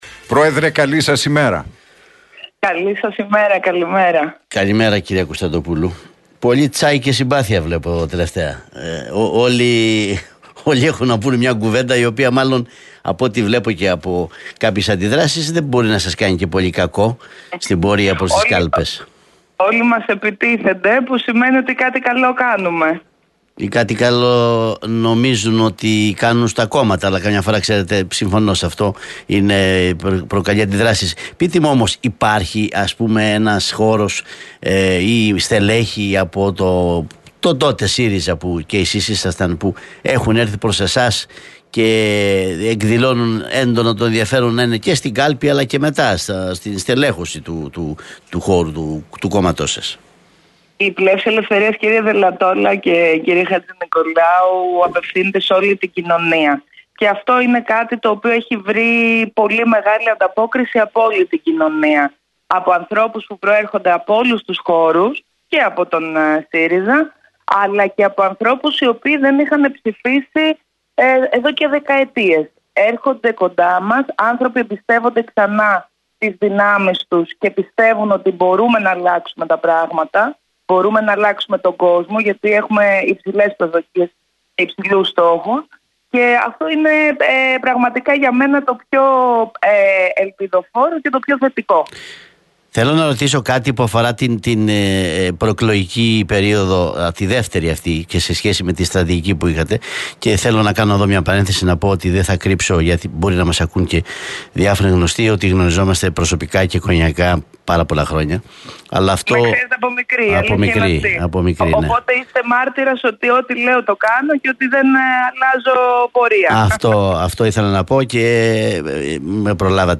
δήλωσε η Ζωή Κωνσταντοπούλου στον Realfm 97,8, στην εκπομπή του Νίκου Χατζηνικολάου